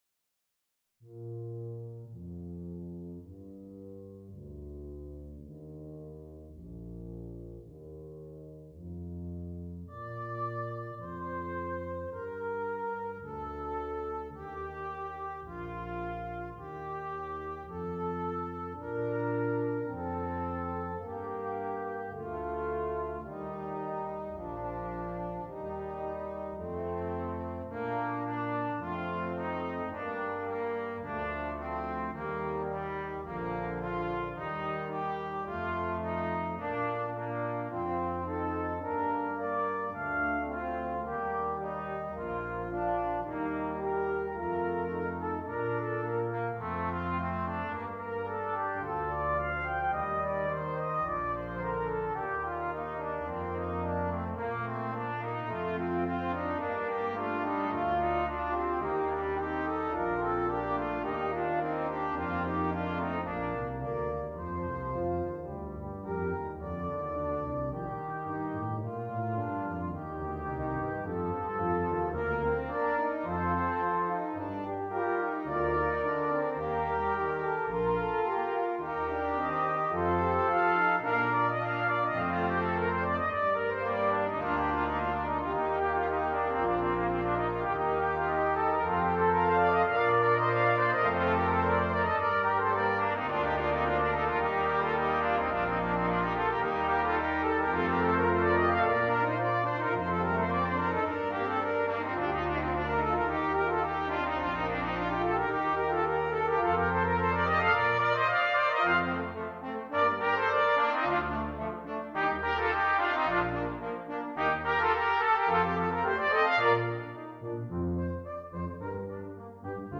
Brass Quintet
Difficulty: Medium-Difficult Order Code